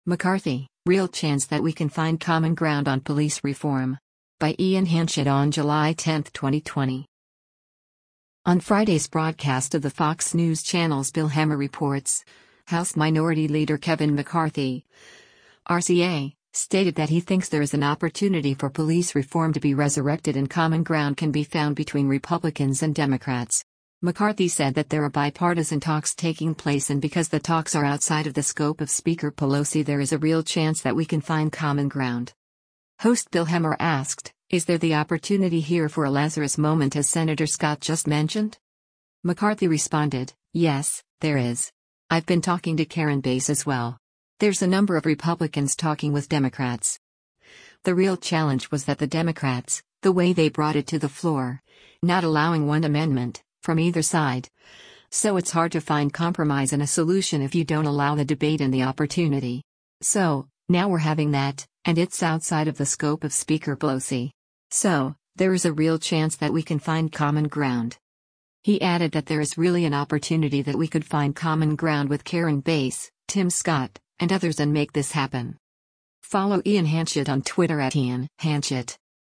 On Friday’s broadcast of the Fox News Channel’s “Bill Hemmer Reports,” House Minority Leader Kevin McCarthy (R-CA) stated that he thinks there is an opportunity for police reform to be resurrected and common ground can be found between Republicans and Democrats.
Host Bill Hemmer asked, “Is there the opportunity here for a Lazarus moment as Sen. Scott just mentioned?”